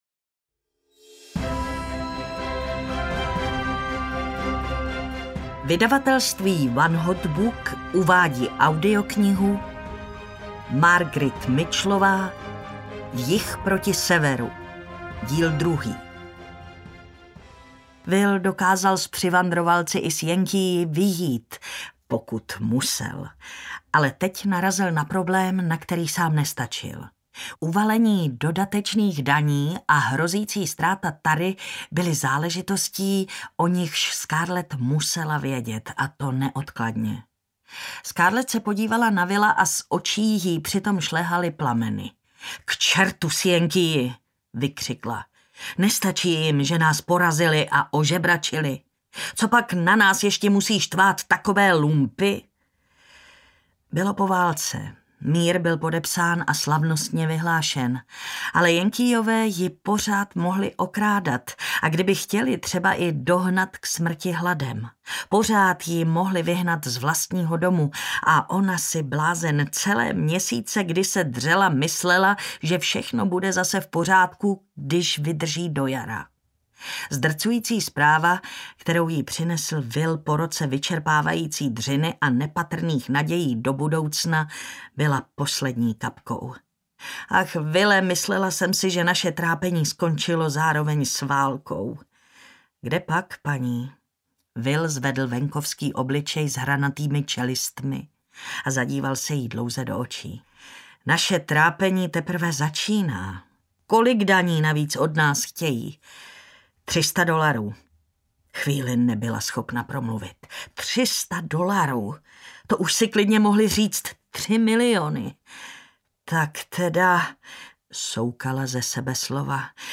Jih proti Severu II audiokniha
Ukázka z knihy